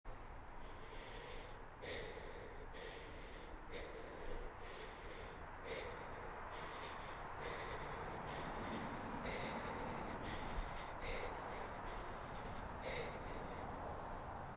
Download Free Breathing Sound Effects
Breathing